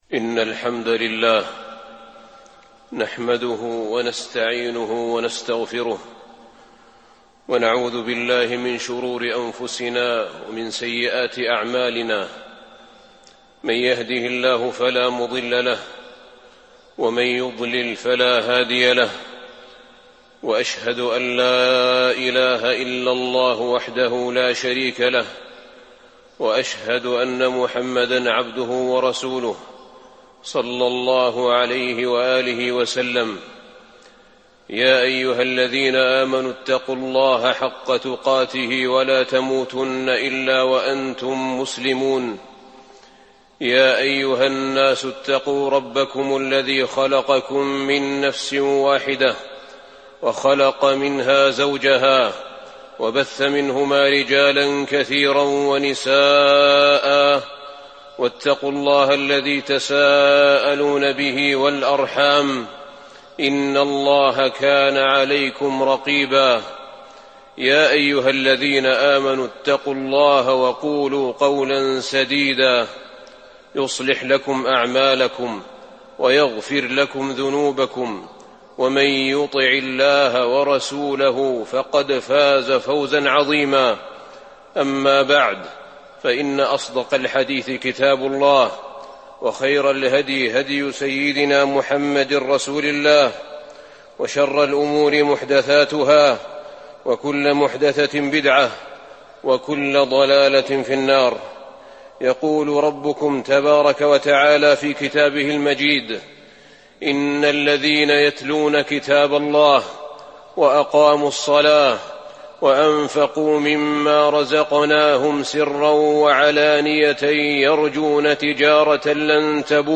تاريخ النشر ٢٠ شعبان ١٤٤٢ هـ المكان: المسجد النبوي الشيخ: فضيلة الشيخ أحمد بن طالب بن حميد فضيلة الشيخ أحمد بن طالب بن حميد آية الفلاح وعلامة الشقاوة